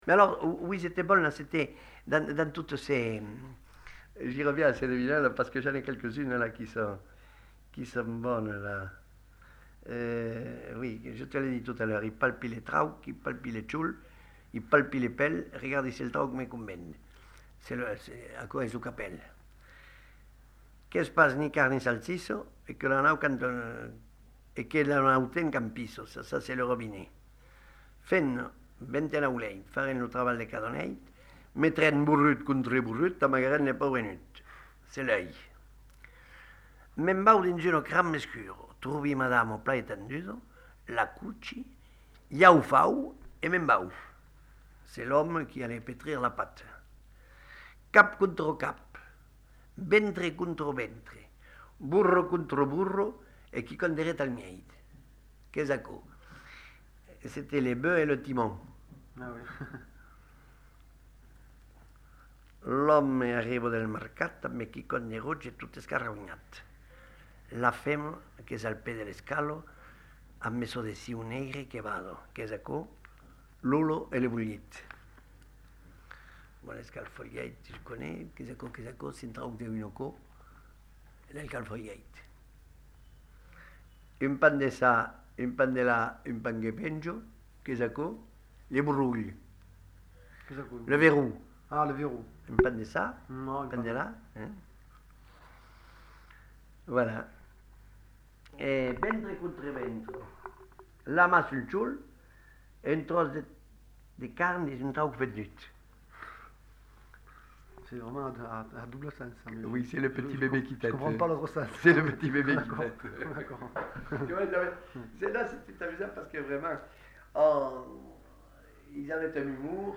Devinettes
Aire culturelle : Lauragais
Effectif : 1
Type de voix : voix d'homme
Production du son : récité
Classification : devinette-énigme